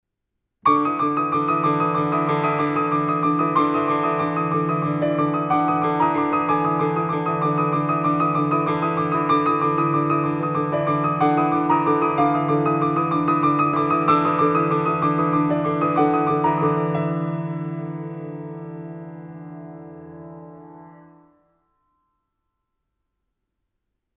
piano
Recorded at Radiostudio Zürich
Bildhafte Klaviermusik
Überwiegend meditative Klavierklänge
Meditativ